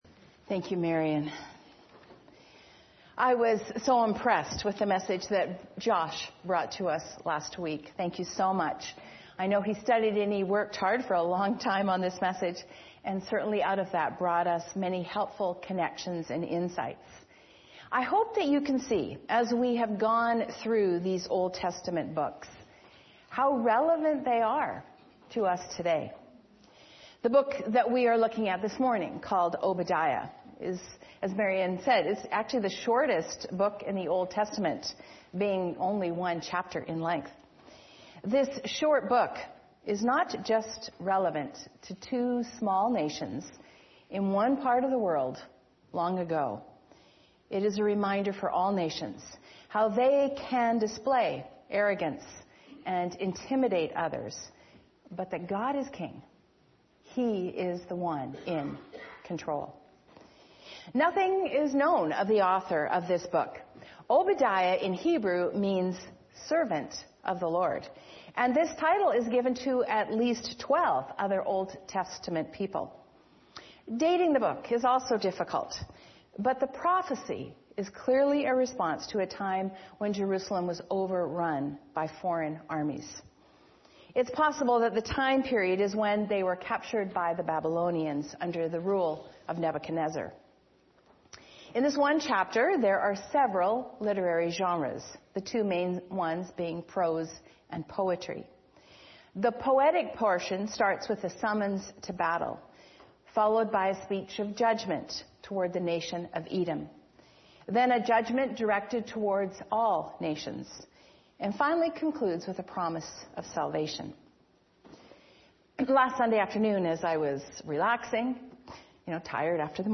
Sermons | Olivet Baptist Church